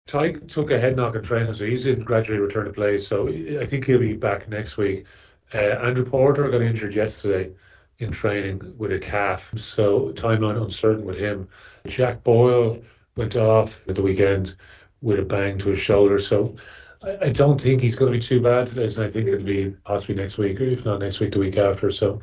Cullen has been explaining his injury headache this week.